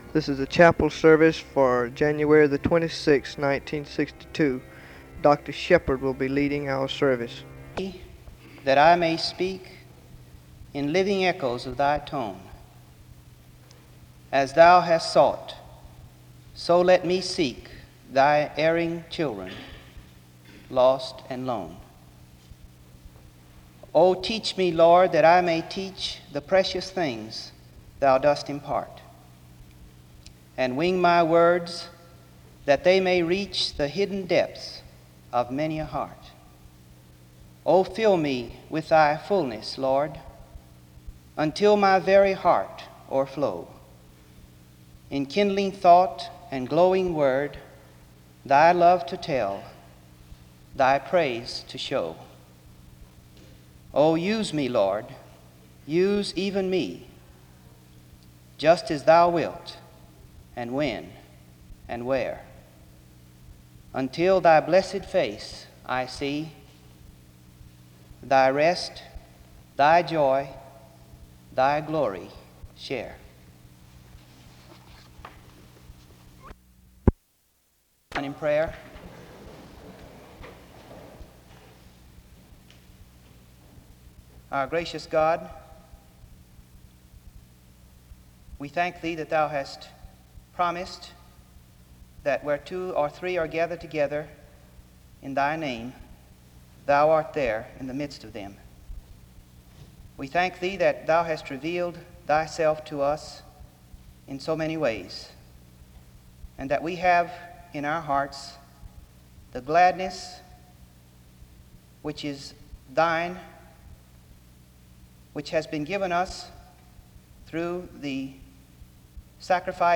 The service begins with prayer (00:00-02:39) and the reading of Luke 24:44-49 (02:40-04:06).
He ends in prayer (22:29-23:17).